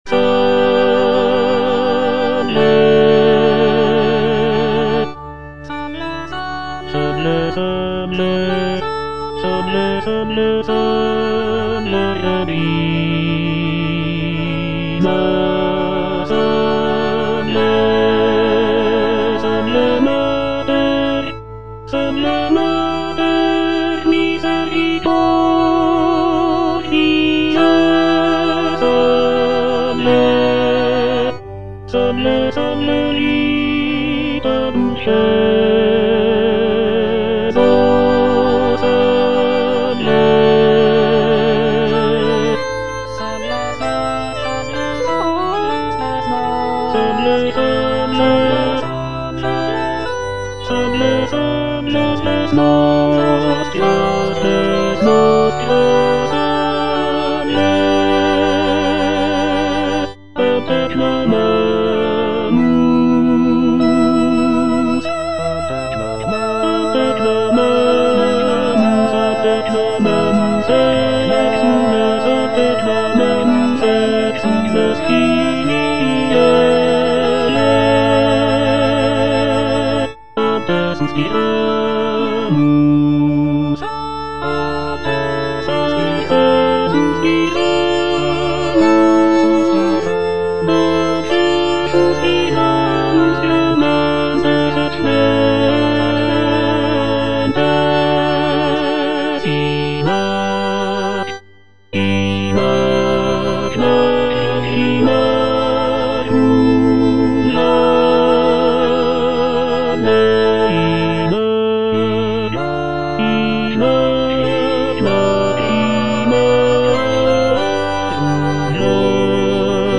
G.F. SANCES - SALVE, REGINA Tenor (Emphasised voice and other voices) Ads stop: auto-stop